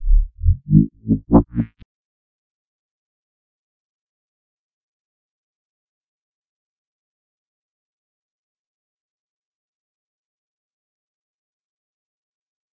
摇摆不定的贝司声 " 贝司重采样8
描述：音乐制作的疯狂低音
Tag: 重采样 重低音 音效设计 摇晃